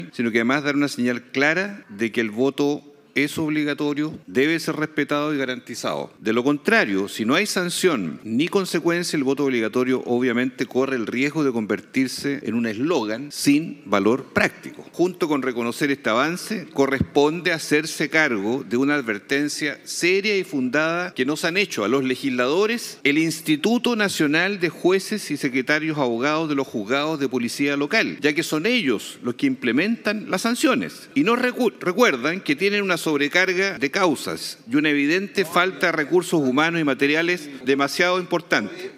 Durante la discusión, el senador José Luis Castro subrayó que el voto obligatorio debe ir acompañado de sanciones claras, pues de lo contrario corre el riesgo de transformarse en “un eslogan sin valor práctico”.